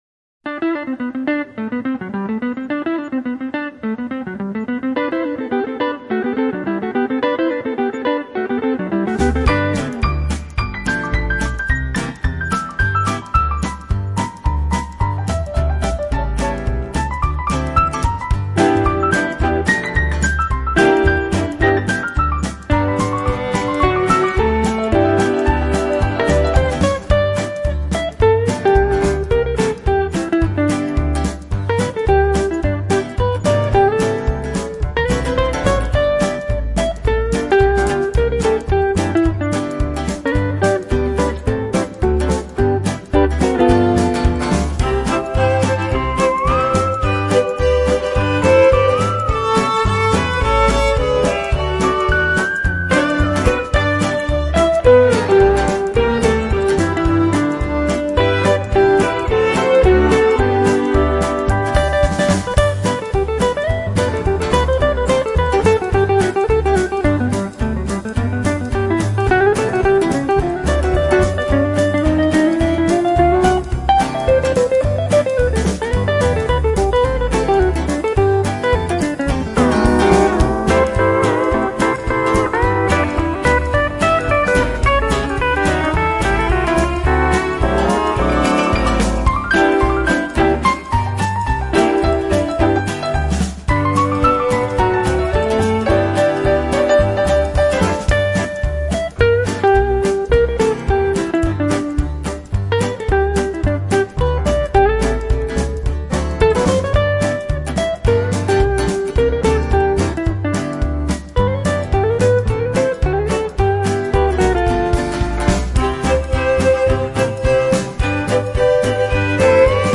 دسته بندی : پاپ